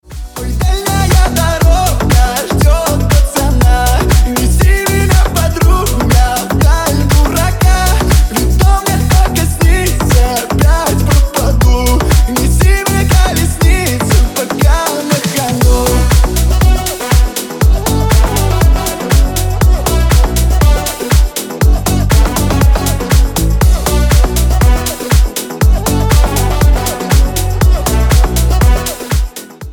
• Песня: Рингтон, нарезка